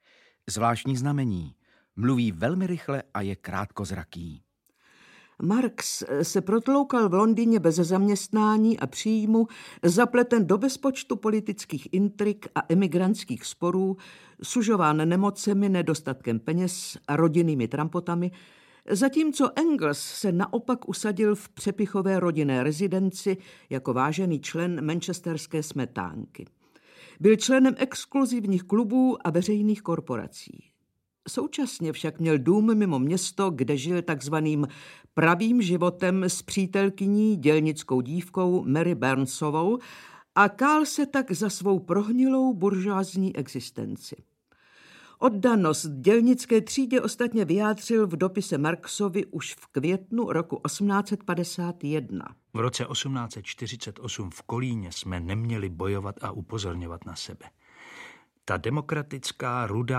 Audiobook
Audiobooks » Short Stories